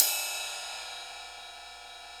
RIDE17.wav